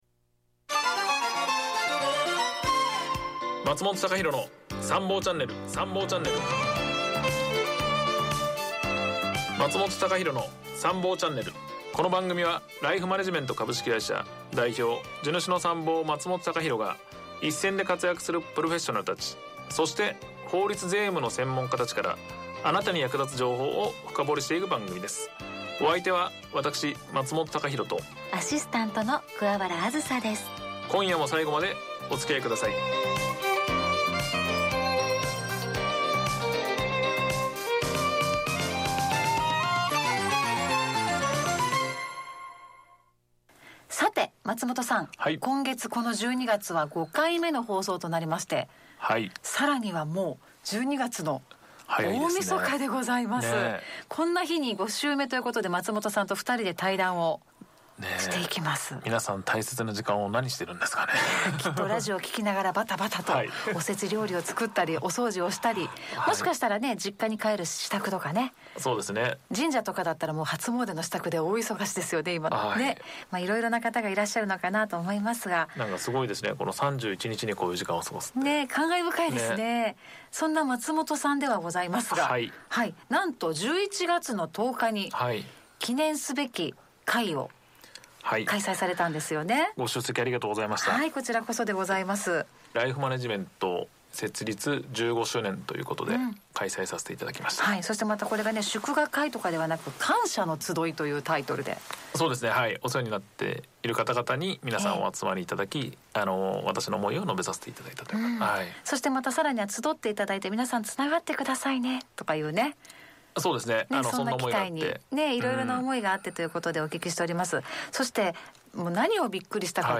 2人だけでじっくり語り合う対談をお届けします
番組後半では、 「自分の未来に遠慮はないか」 という一言に込めた想いや、これから見据えている“次のステージ”についても静かに語ります。